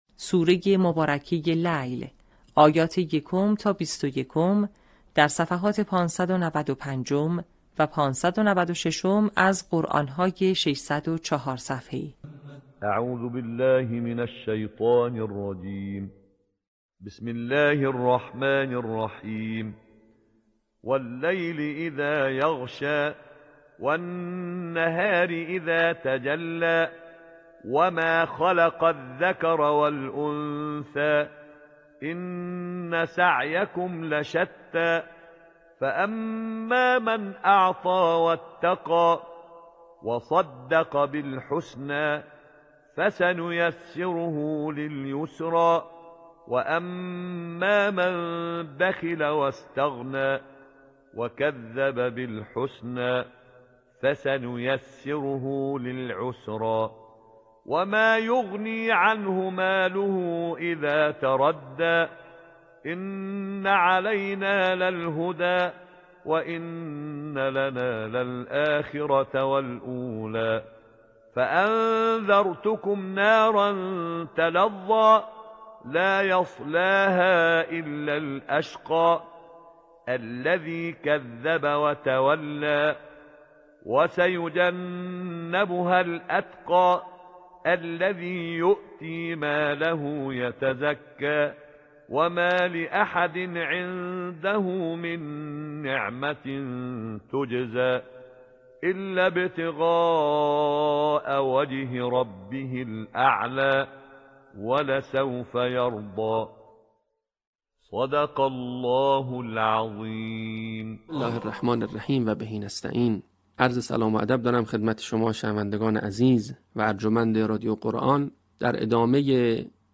صوت | آموزش حفظ جزء ۳۰، آیات ۱ تا ۲۱ سوره لیل